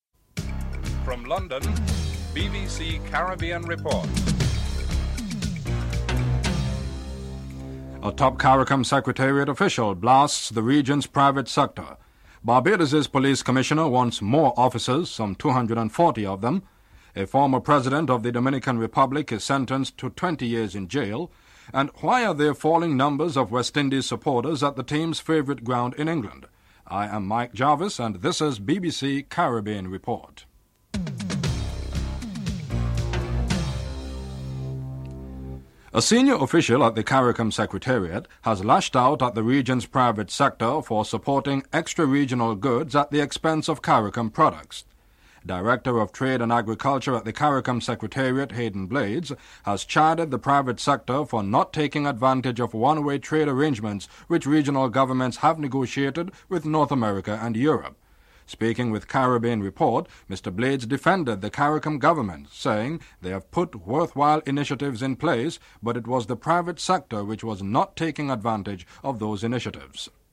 1. Headlines (00:00-00:35)